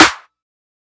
OZ-Clap 8.wav